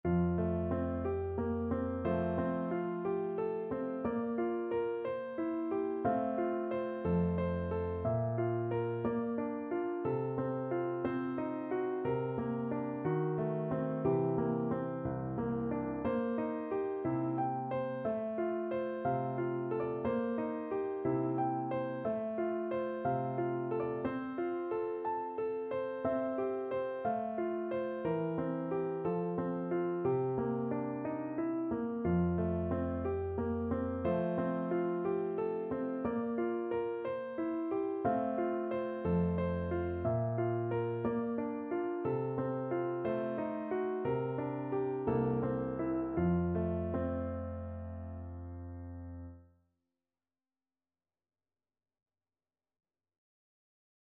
No parts available for this pieces as it is for solo piano.
2/4 (View more 2/4 Music)
~ = 60 Andantino (View more music marked Andantino)
Piano  (View more Intermediate Piano Music)
Classical (View more Classical Piano Music)